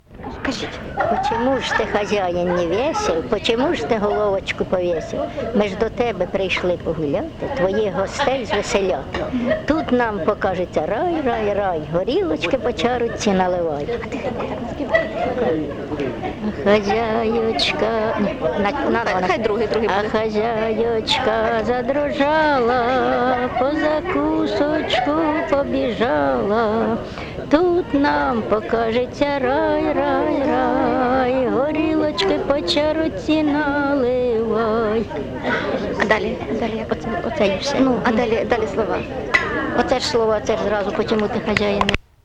ЖанрЖартівливі
Місце записус. Очеретове, Валківський район, Харківська обл., Україна, Слобожанщина